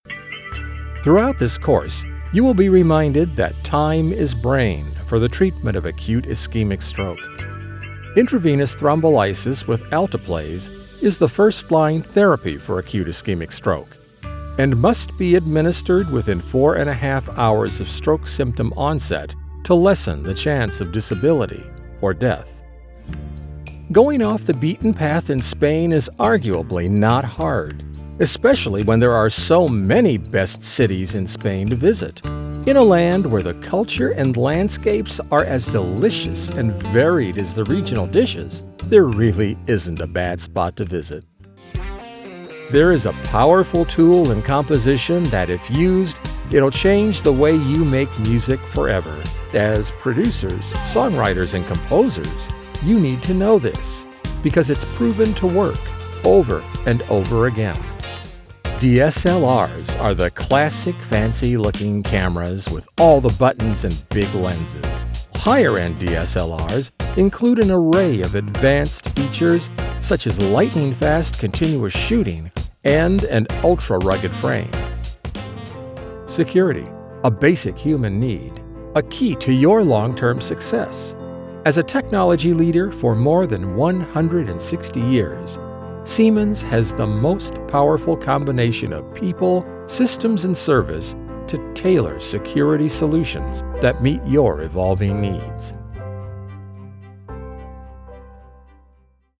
e-Learning Demo